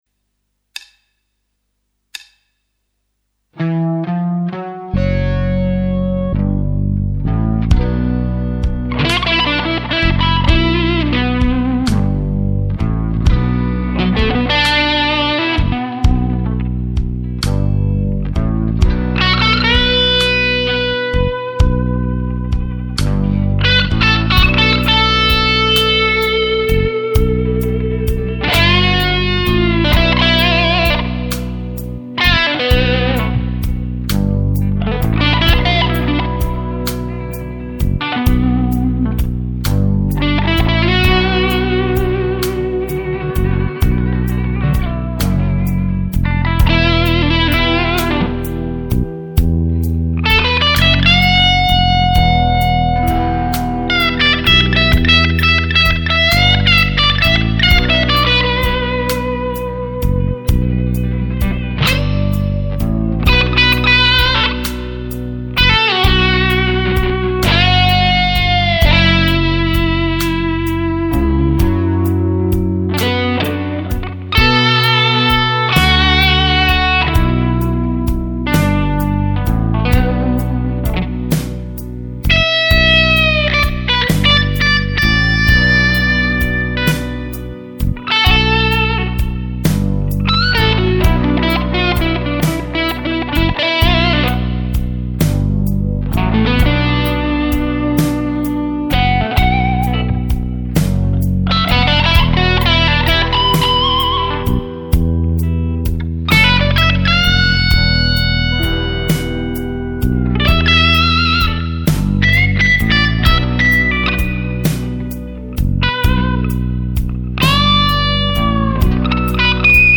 Damage Control Womanizer mit Tokai LS 320
dc_womanizer_lespaul.mp3